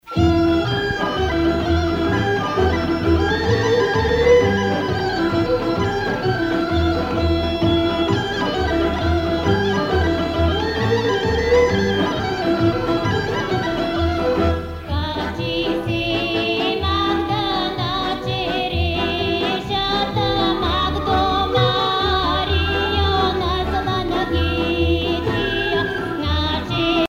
Pièce musicale orchestrée n°11
Bulgarie
Pièce musicale inédite